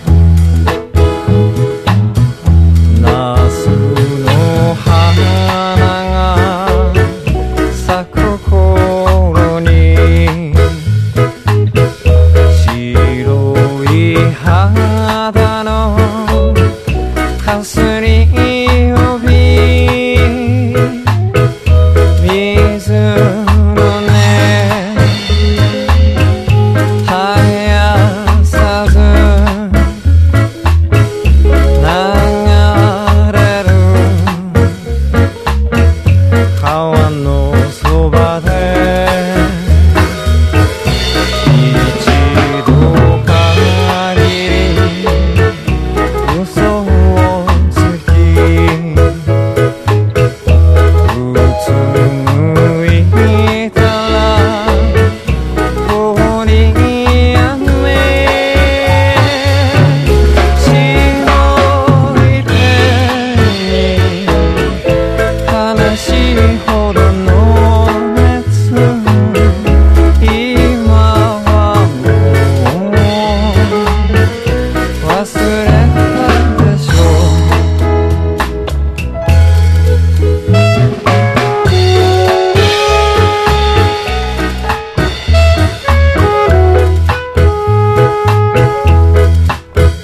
NEO SKA / 2 TONE SKA / NEW WAVE